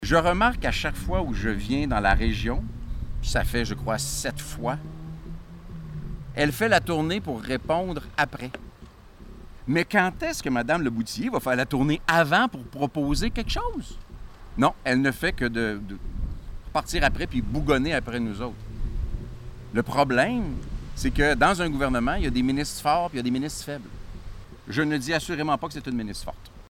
Le Chef du Bloc Yves François Blanchet a profité du bilan du caucus pour lancer une nouvelle flèche à la députée libérale Diane Lebouthillier qui s’est montrée irritée par les propos des bloquistes concernant son travail :